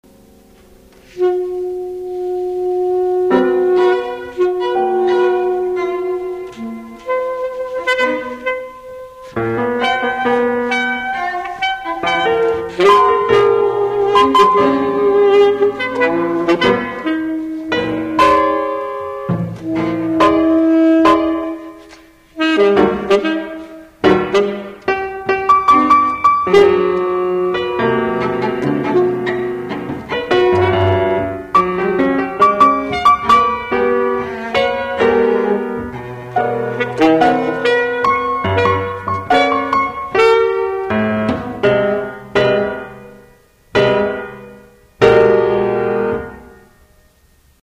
for alto sax. or Eng. hn., vlc. and piano
Rock Hall, Philadelphia, January 1997